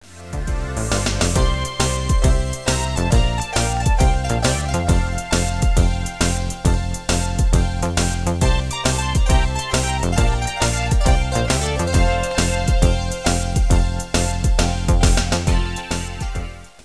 4:30 - 136 bpm - 12 September 1999